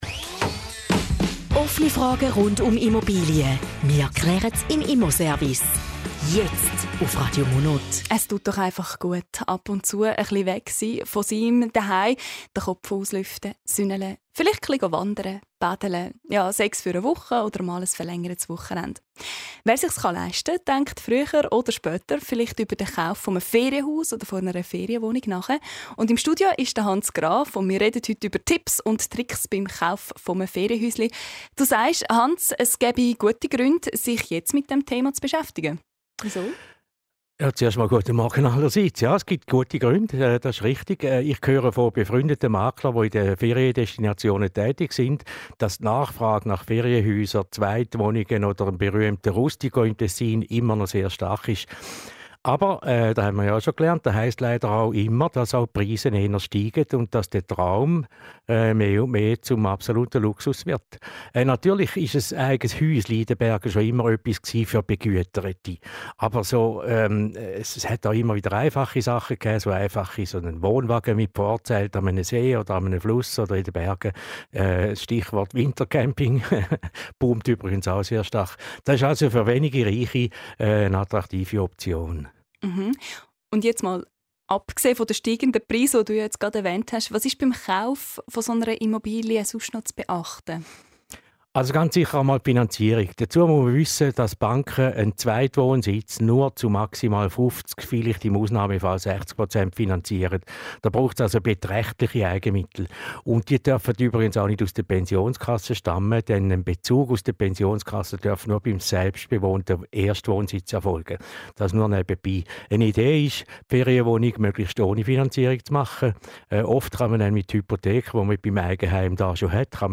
Zusammenfassung des Interviews zum Thema "Tipps für den Kauf eines Ferienhauses":